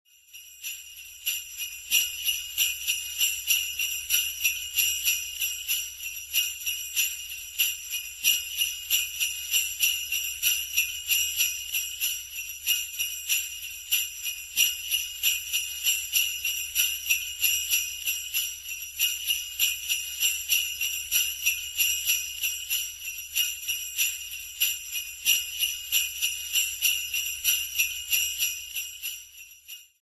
Sleigh Bells (Long Version)
SleighBells-LongVersion.mp3